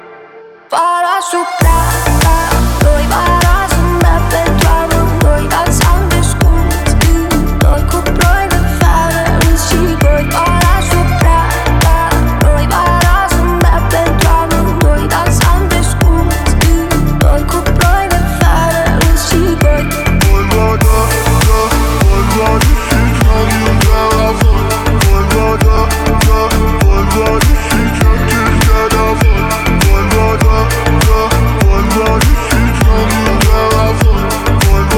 2025-08-19 Жанр: Поп музыка Длительность